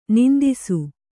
♪ nindisu